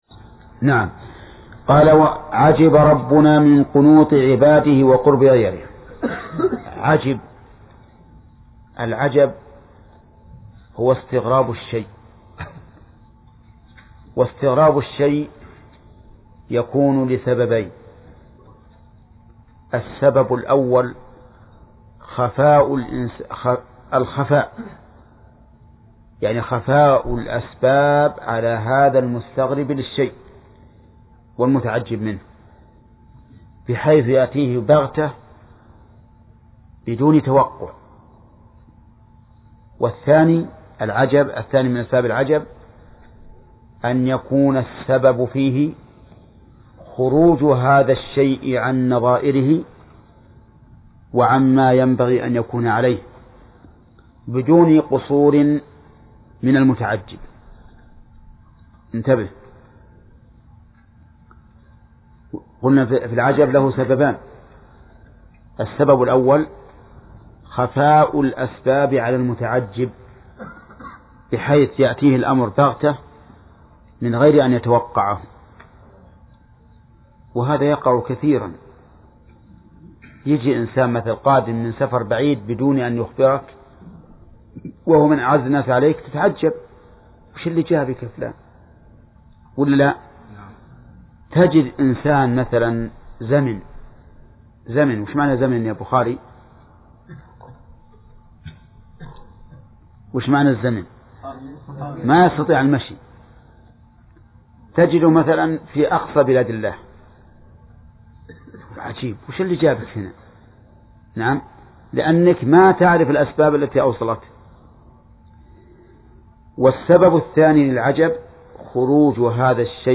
درس (24) : من صفحة: (26)، (قوله: الحديث الرابع ....)، إلى صفحة: (47)، (قوله: الحديث الرابع عشر ....).